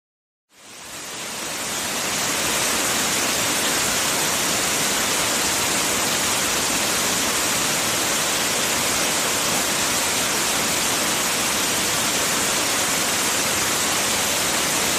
River Rapid
River Rapid is a free nature sound effect available for download in MP3 format.
528_river_rapid.mp3